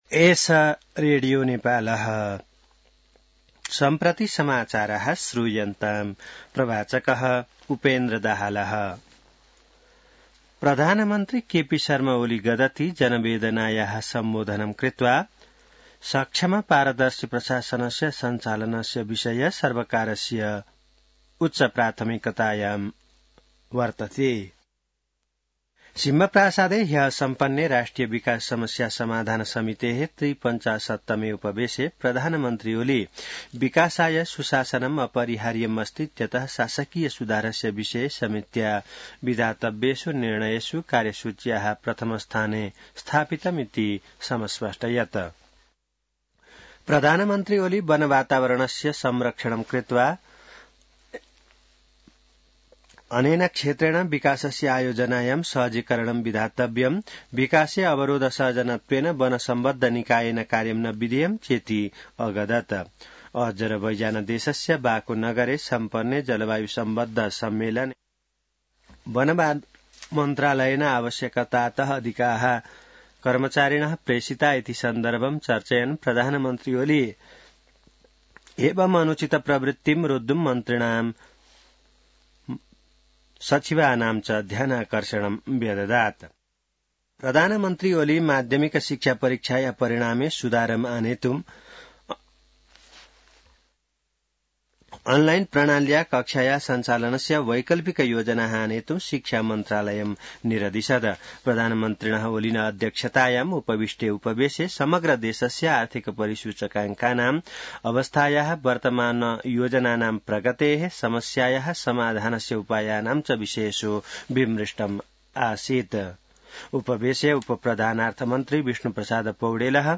संस्कृत समाचार : २ मंसिर , २०८१